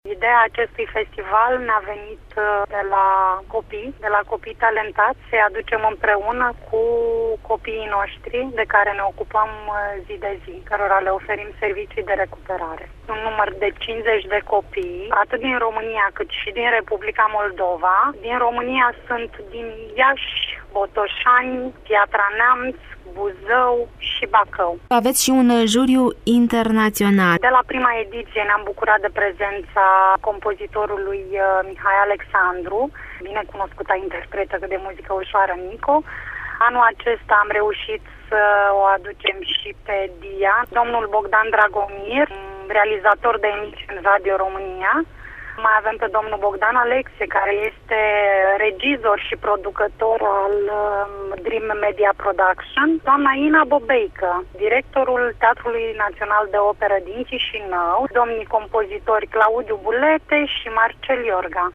în dialog cu redactorul nostru